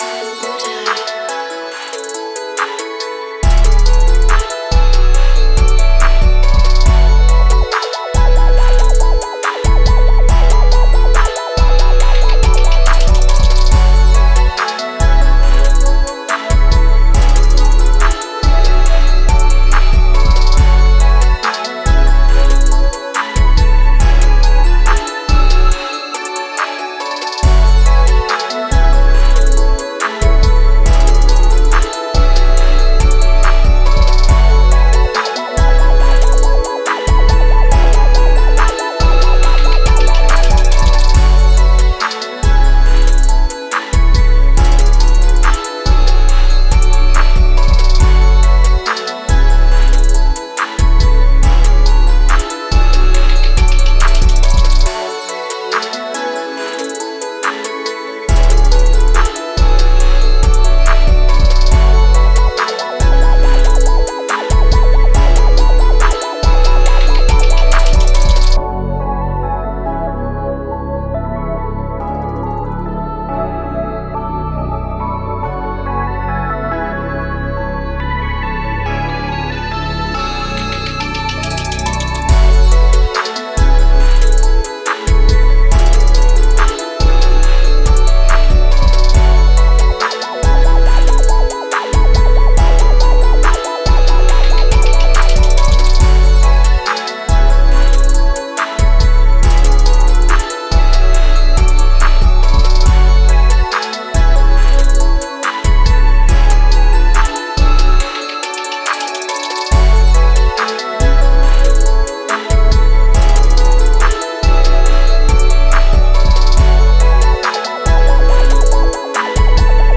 Time – (2:32)　bpm.140